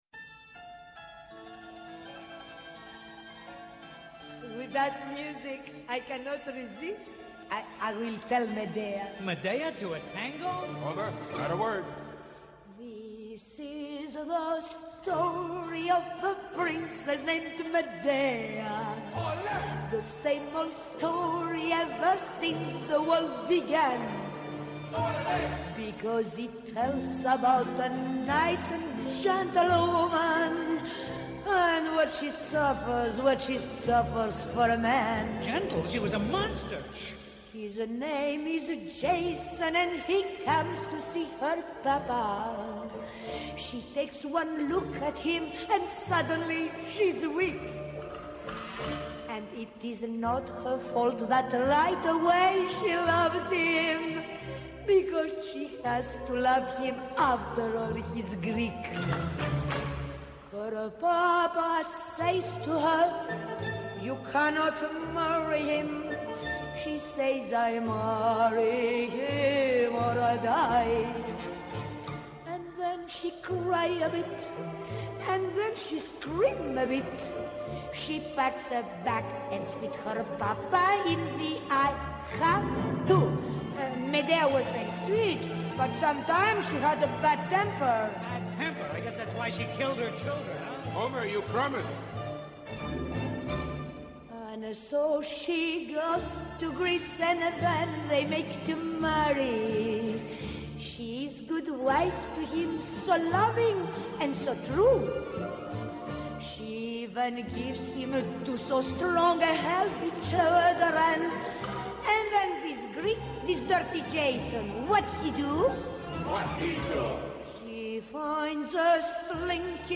Μια εκπομπή για το ντοκιμαντέρ και τους δημιουργούς του στο Α’ Πρόγραμμα της ΕΡΤ.